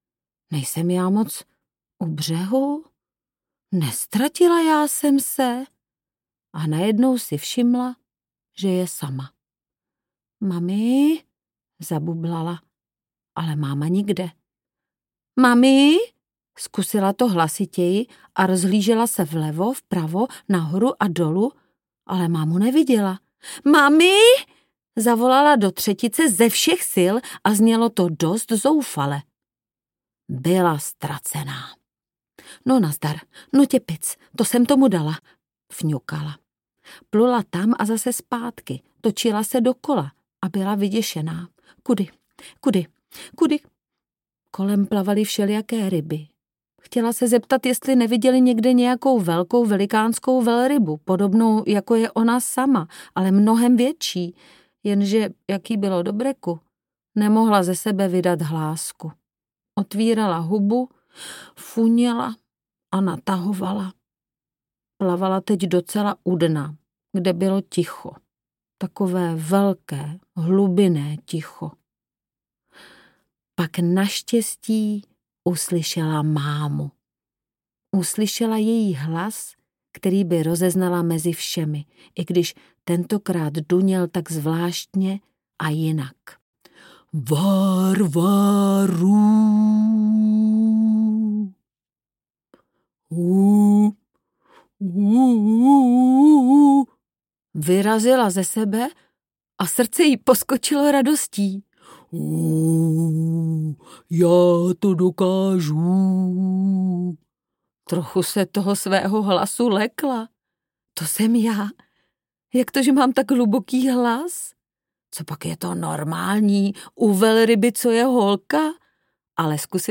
Varvara audiokniha
Ukázka z knihy